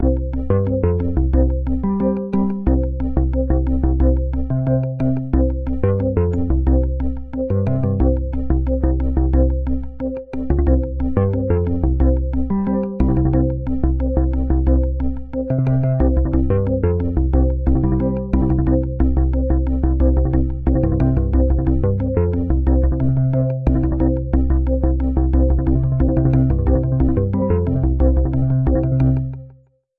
outgoing-call.mp3